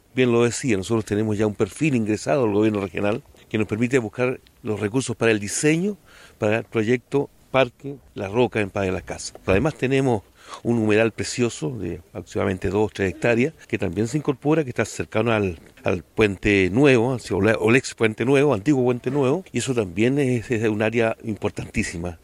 El alcalde de Padre Las Casas, Mario González, reveló el avance que presenta para la comuna que administra el emblemático proyecto del parque Cautín por el sector Las Rocas, el que además busca unirse por un puente peatonal y para bicicletas con el parque similar en Temuco.